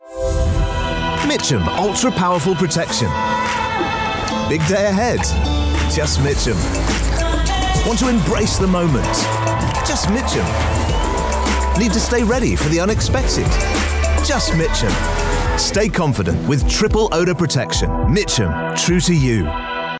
Male
Television Spots